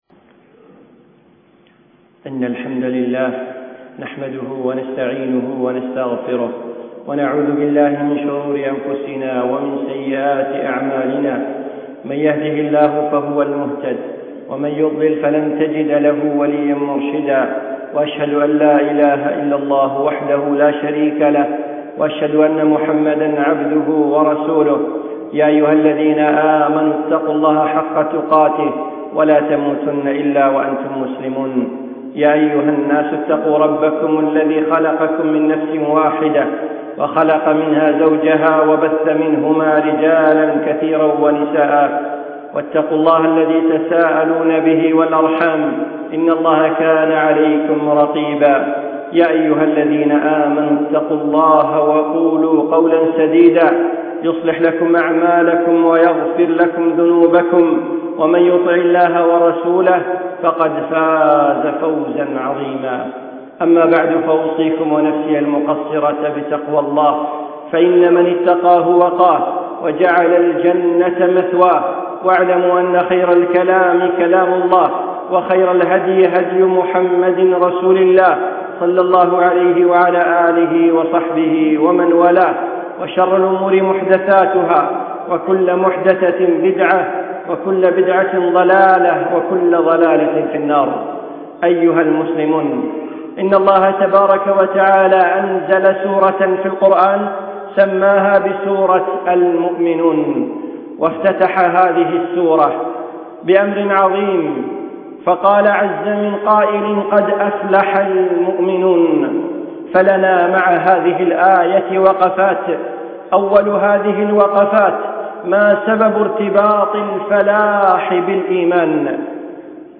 من خطبة الشيخ في دولة الإمارات